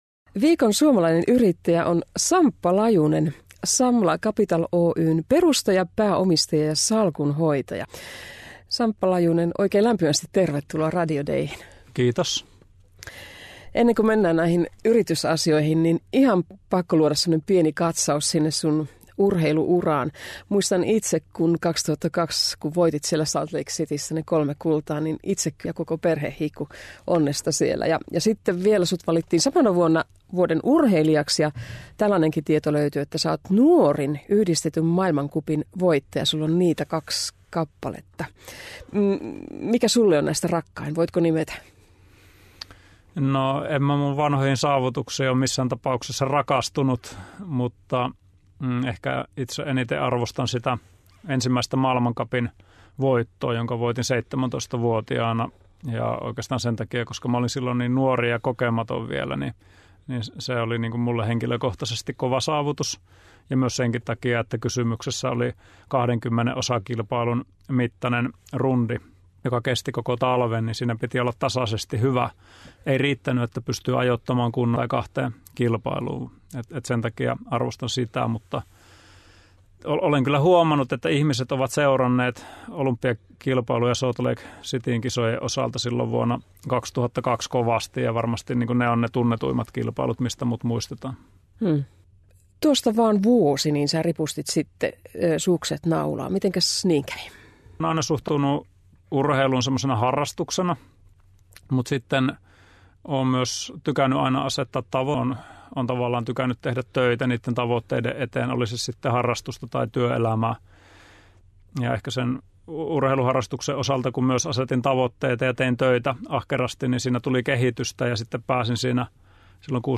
Samla Capital Oy:n jyväskyläläinen salkunhoitaja ja olympiavoittaja Samppa Lajunen kertoo oman elämänsä käännekohdista sekä yhtiön hallinnoimista Asunto- ja Toimitilarahastoista Viikon suomalainen yrittäjä –ohjelmassa.